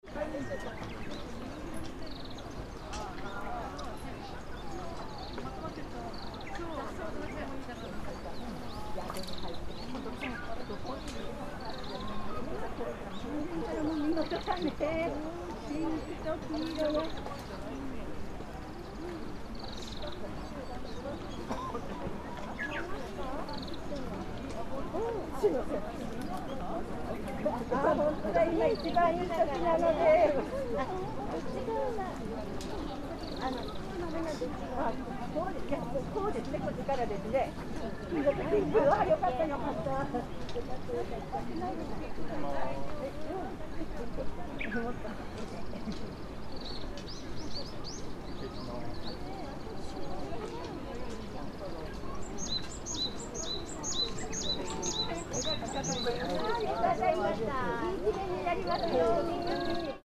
Trail at Mt. Hanami
Because of the excursion of a Japanese Language School, so many people from foreign countries were there.
The voices of many wild birds, like Japanese nightingales and
pheasants, were heard as usual year.